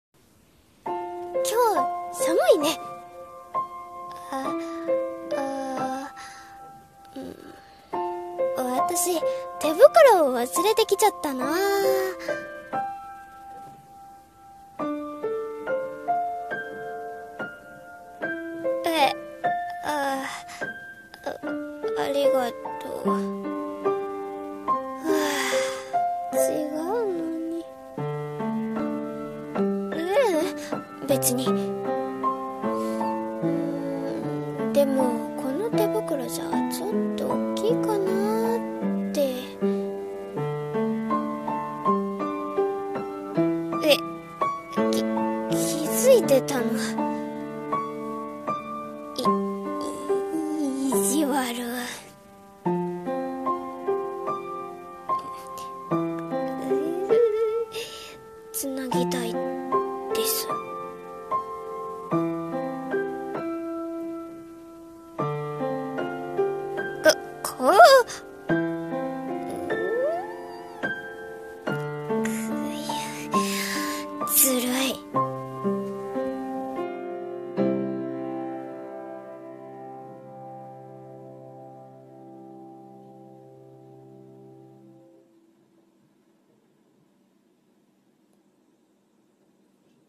【声劇】手を繋ぎたくて【掛け合い】